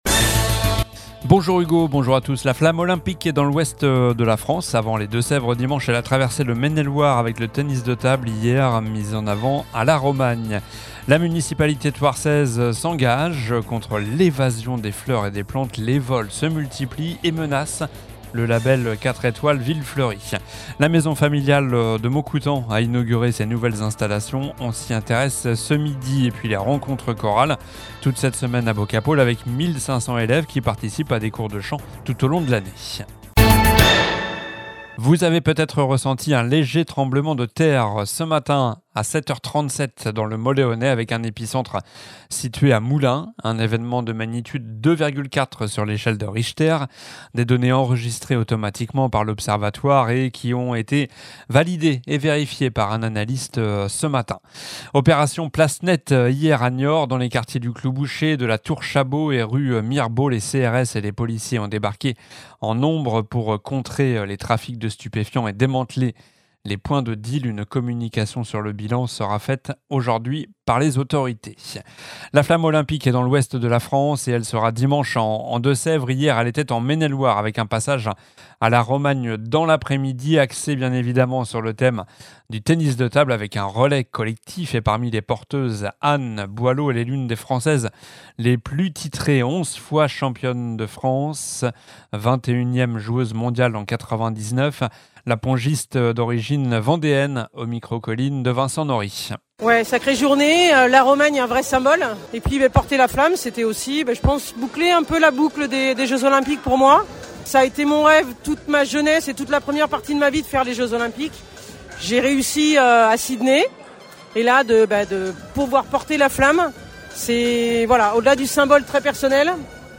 Journal du mercredi 29 mai (midi)